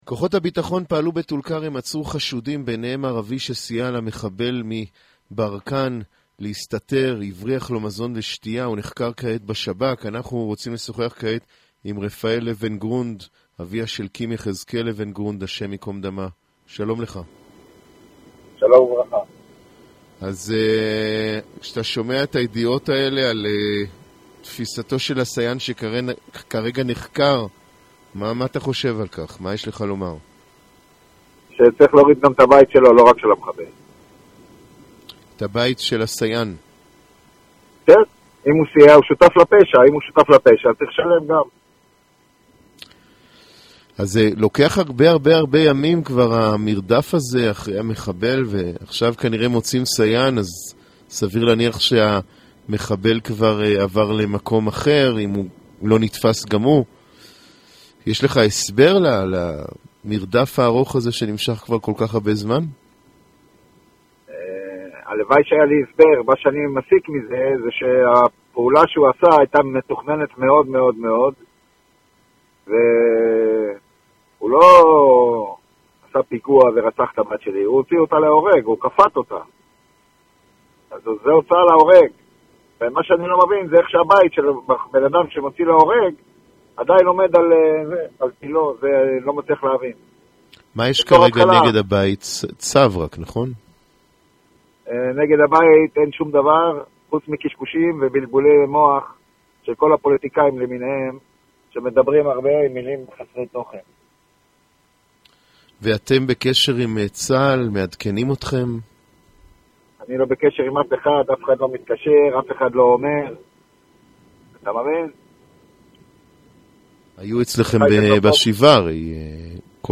Аудиозапись интервью